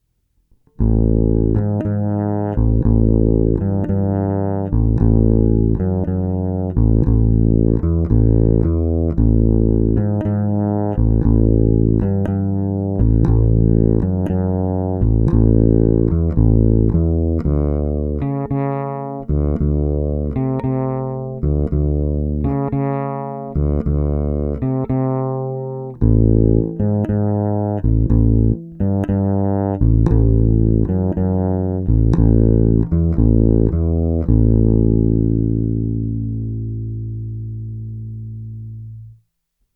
Následující nahrávky jsou vyvedeny rovnou do zvukovky a kromě normalizace ponechány bez úprav.
Hráno všechno s polohou pravé ruky u krku.
Snímač u kobylky